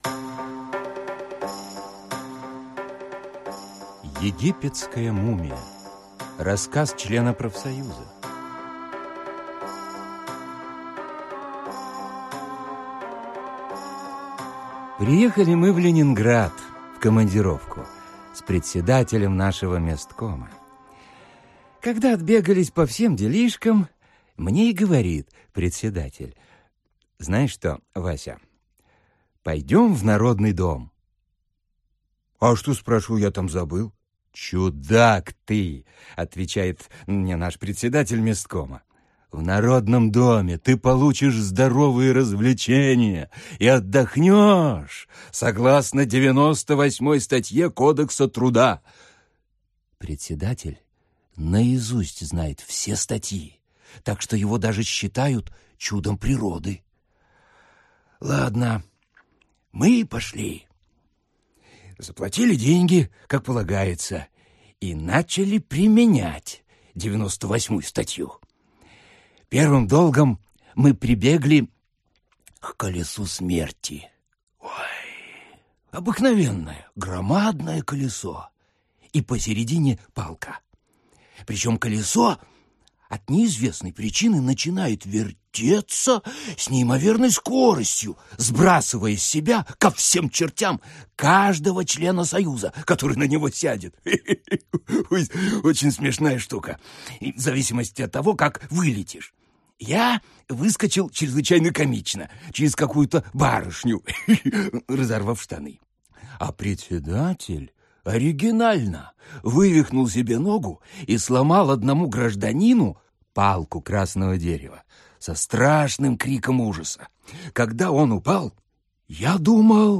Аудиокнига Сатирические рассказы и фельетоны | Библиотека аудиокниг
Aудиокнига Сатирические рассказы и фельетоны Автор Михаил Булгаков Читает аудиокнигу Борис Плотников.